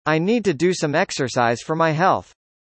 例えば「need to」と言うときは実際の発音を聞いていると”neeto”と1つの単語のように聞こえます。
上記の文章を発音するときには「I neeto-do some exercise for my health.」と ”neeto-do”の部分が続けて発音されます。